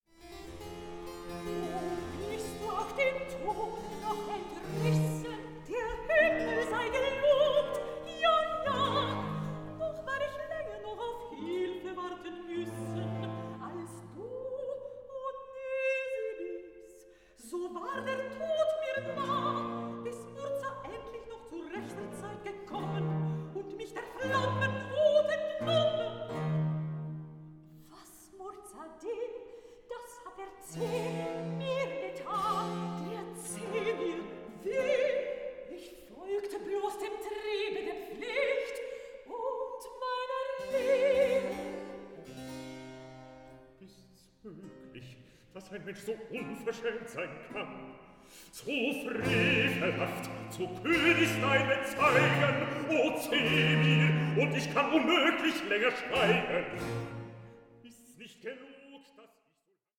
ORIENTAL BAROQUE OPERA